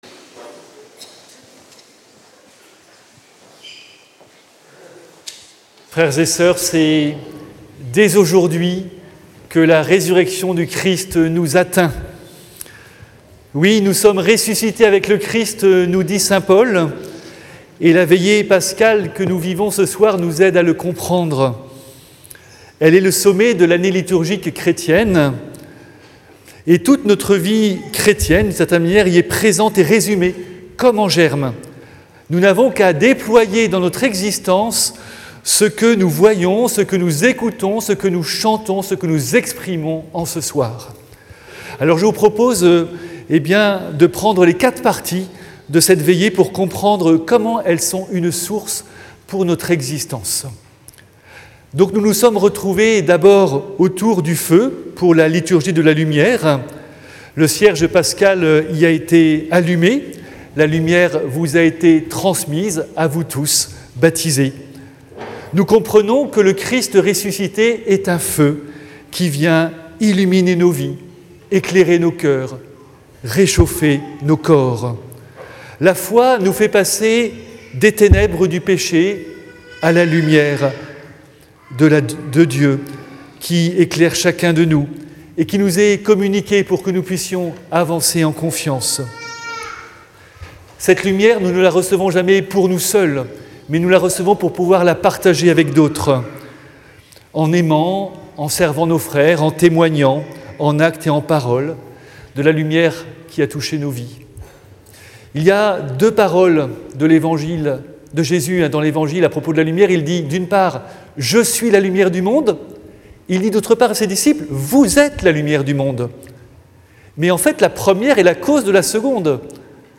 Audio : homélie Veillée Pascale